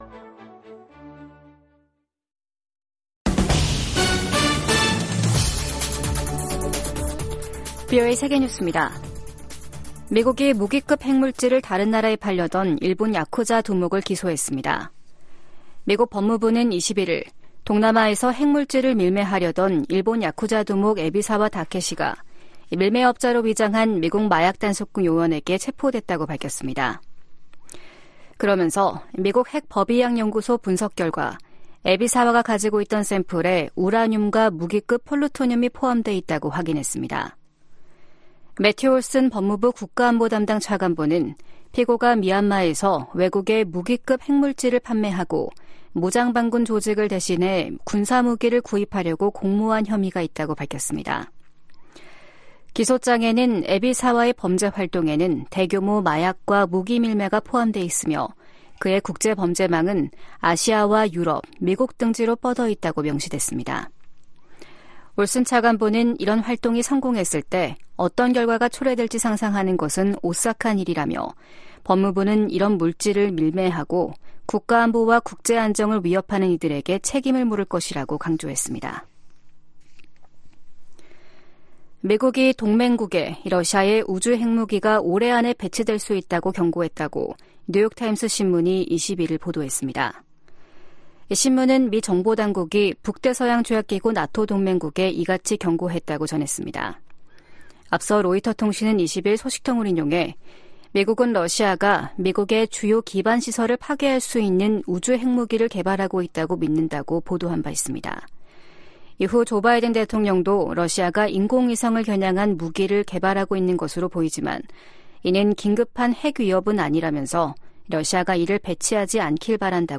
VOA 한국어 아침 뉴스 프로그램 '워싱턴 뉴스 광장' 2024년 2월 23일 방송입니다. 미 국무부가 북한 대량살상무기·탄도미사일에 사용될 수 있는 민감한 품목과 기술 획득을 막는데 모든 노력을 기울일 것이라고 밝혔습니다. 미 하원에서 우크라이나 전쟁 발발 2주년을 맞아 러시아·북한·중국·이란 규탄 결의안이 발의됐습니다. 한국 정부는 북한과 일본 간 정상회담 관련 접촉 움직임에 관해, 한반도 평화 유지 차원에서 긍정적일 수 있다는 입장을 밝혔습니다.